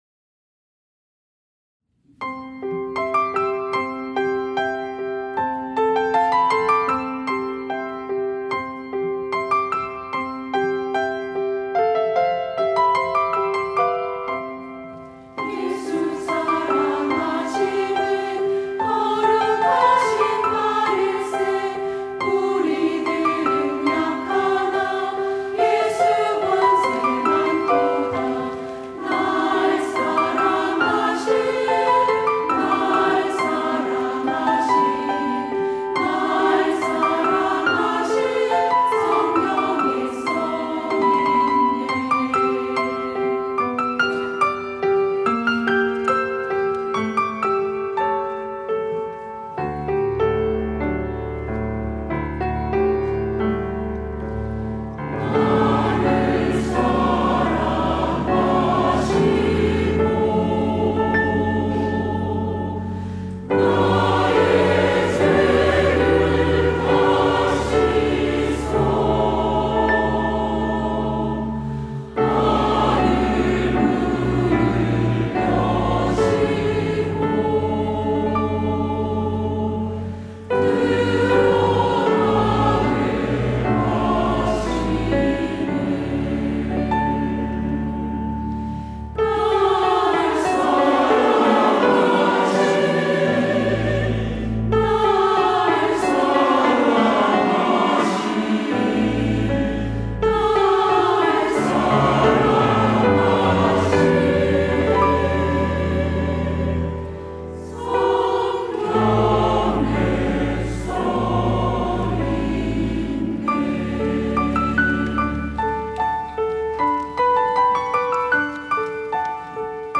시온(주일1부) - 날 사랑하심
찬양대 시온